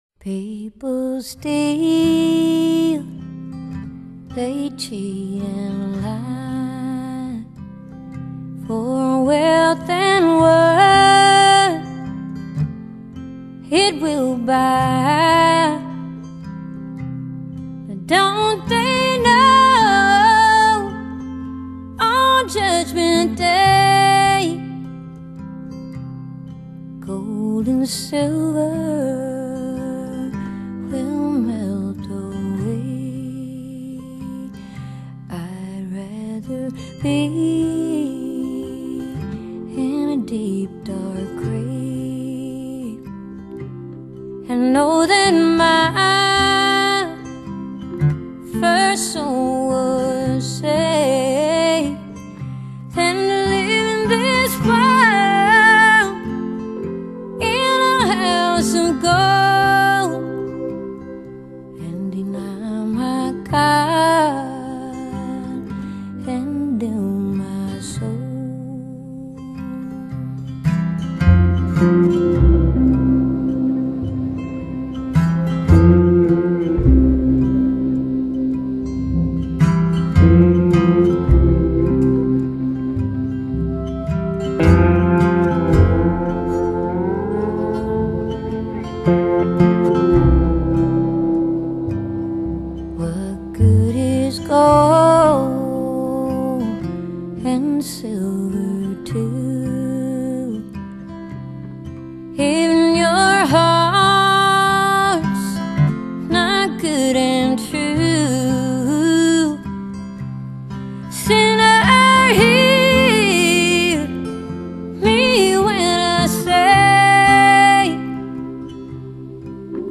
【民謠創作女歌手】
Country / Folk
哀怨的女低音，再加上旋律吉他
a voice that is mournful yet uplifting